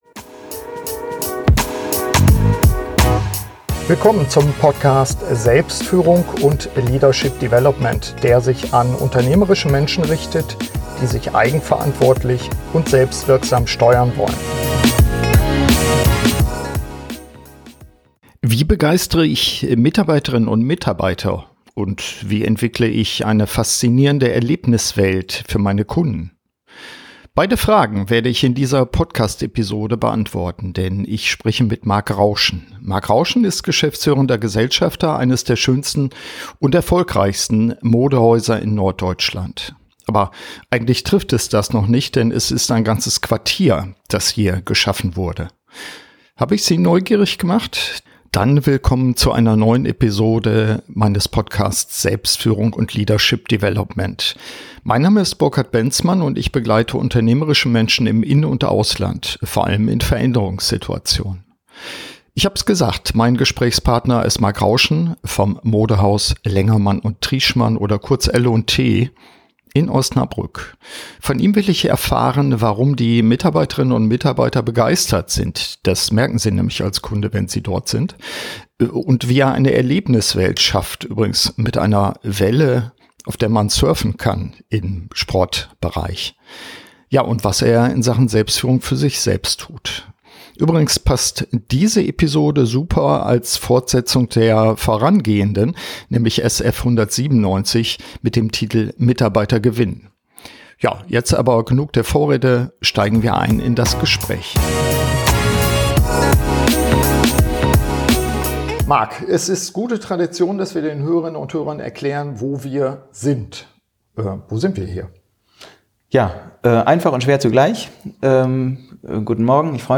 Darüber hinaus sprechen wir darüber, wie eine Dynamik aus Traditionsbewusstsein und Innovationskultur gelebt werden kann. Ein kurzweiliges Gespräch mit vielen Anregungen für Führungskräfte auch aus ganz anderen Branchen...